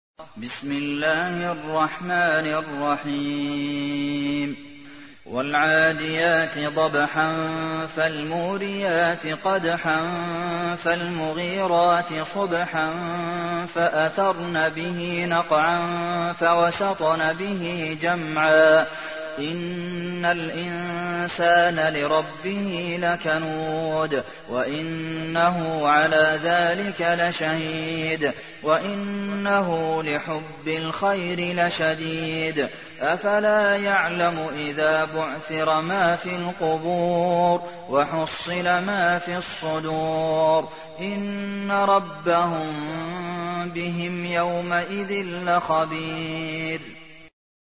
تحميل : 100. سورة العاديات / القارئ عبد المحسن قاسم / القرآن الكريم / موقع يا حسين